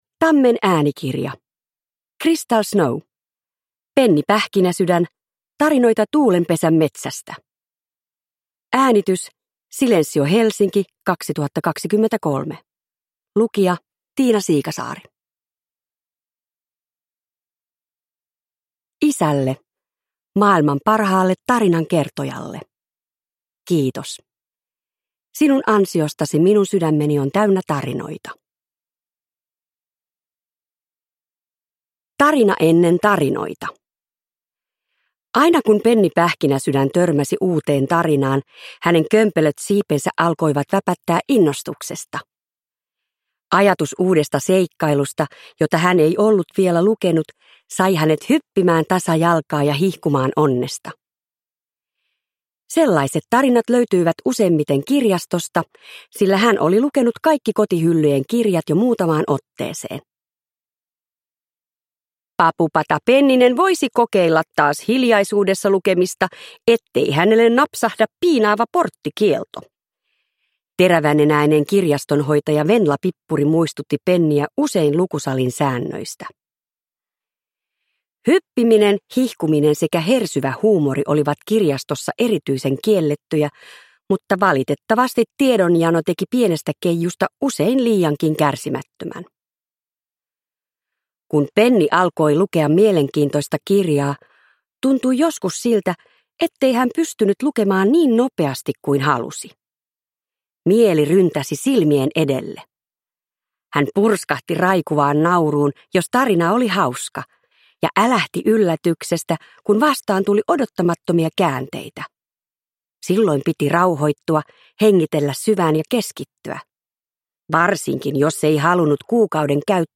Penni Pähkinäsydän. Tarinoita Tuulenpesän metsästä – Ljudbok – Laddas ner